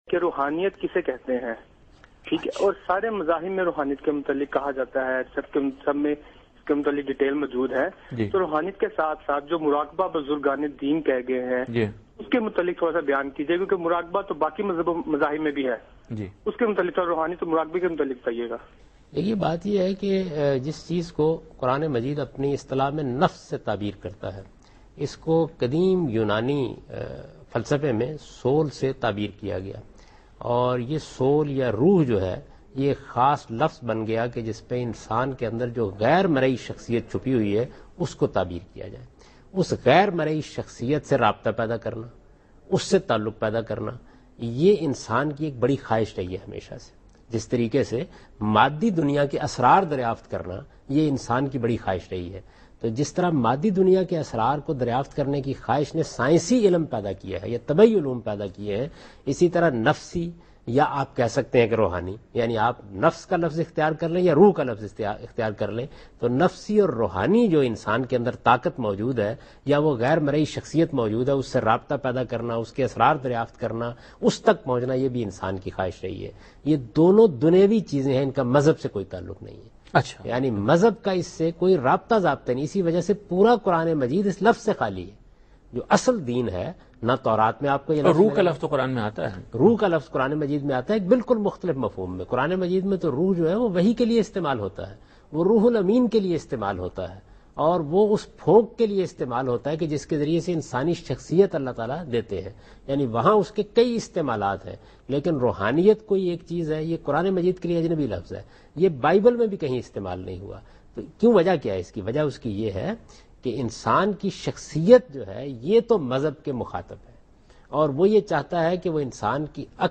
Javed Ahmad Ghamidi Answers a question regarding "Meditation and Spirituality" in program Deen o Daanish on Dunya News.
جاوید احمد غامدی دنیا نیوز کے پروگرام دین و دانش میں روحانیت اور مراقبہ سے متعلق ایک سوال کا جواب دے رہے ہیں۔